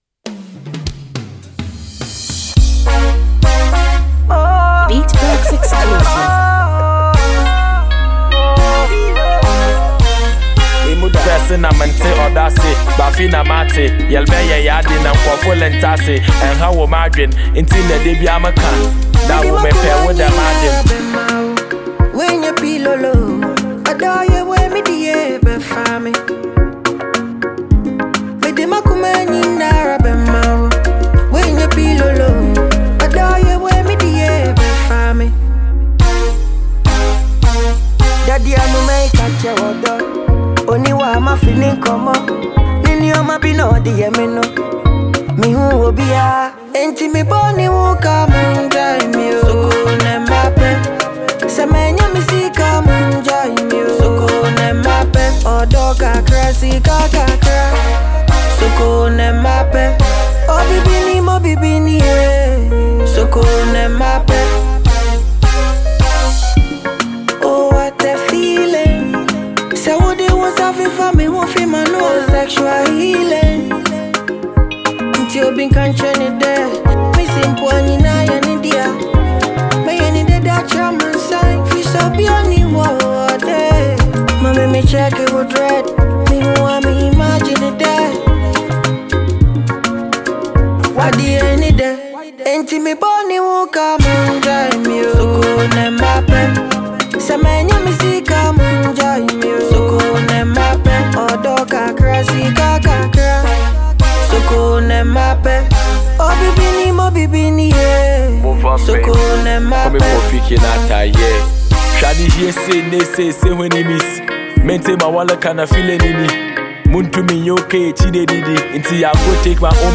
Sensational Ghanaian male vocalist